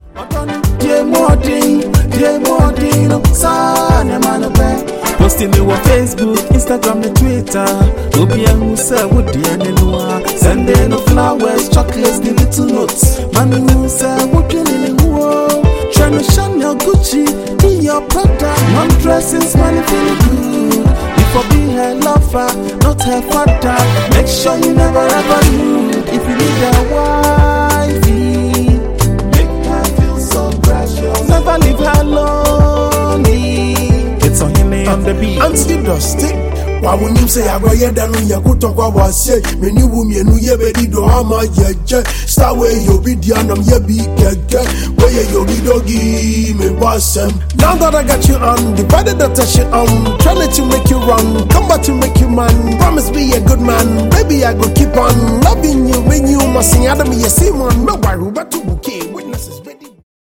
Highlife Music